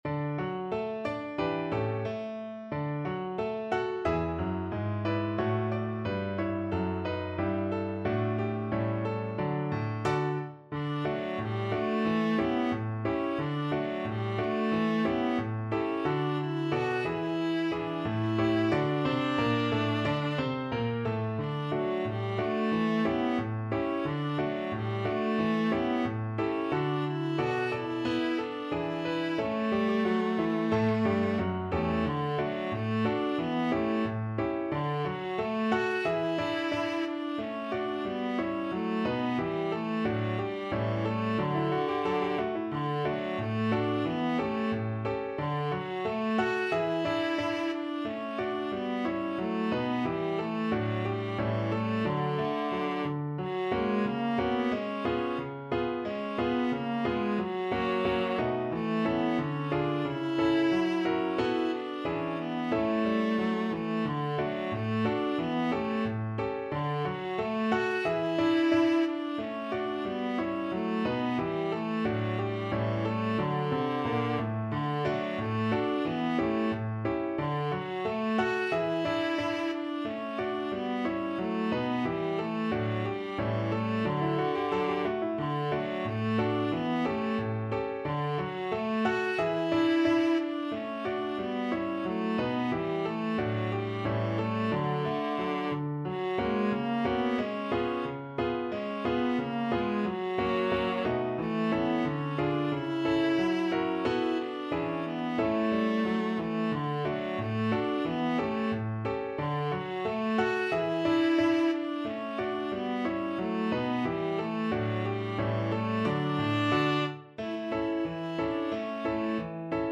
2/2 (View more 2/2 Music)
=90 Fast and cheerful
D4-F#5
Pop (View more Pop Viola Music)